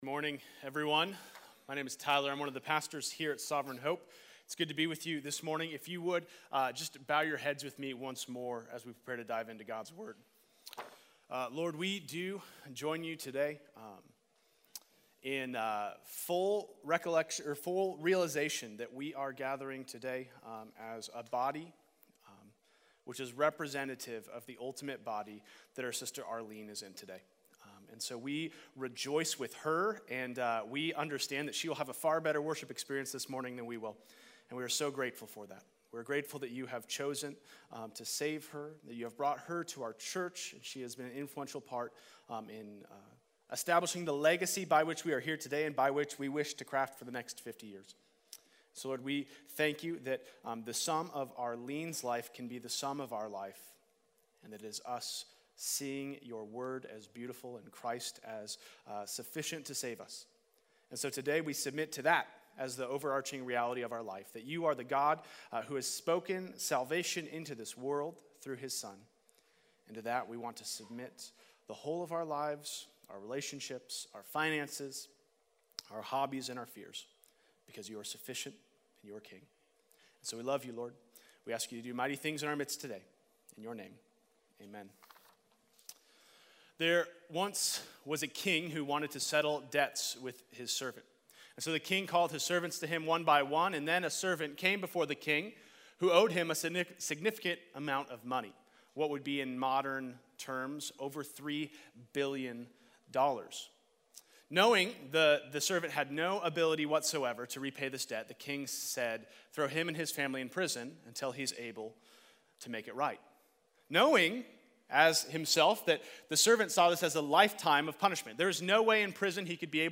In this message on Gospel-Focused Relationships and Families, the purpose and design of marriage is preached. Thus, before Adam and Eve entered into marriage, God intended it to represent a greater love.